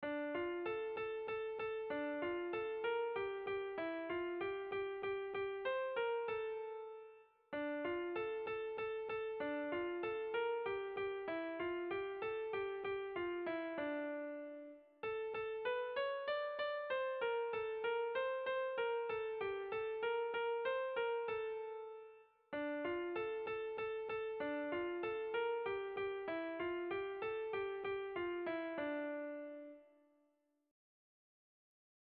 Sentimenduzkoa
Zortziko handia (hg) / Lau puntuko handia (ip)
A1A2BA2